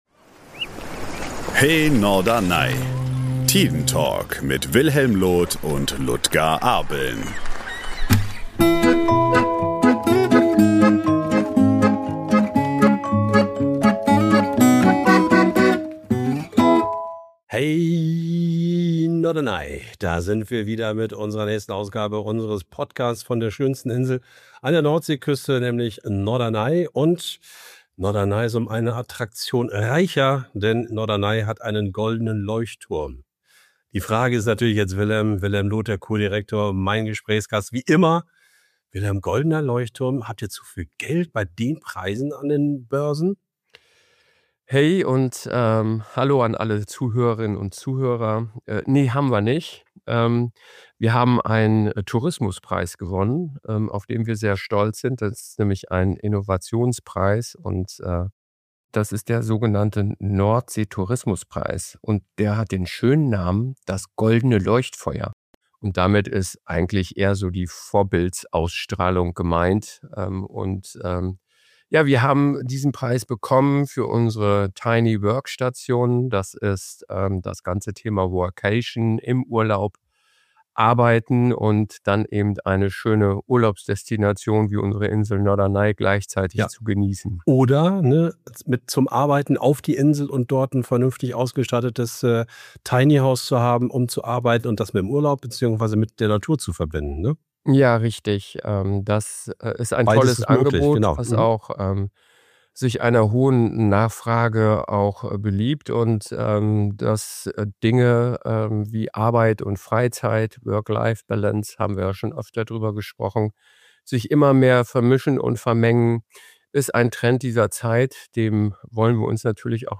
Gleichzeitig spüren die beiden Gesprächspartner, warum Urlaub heute mehr ist als Erholung.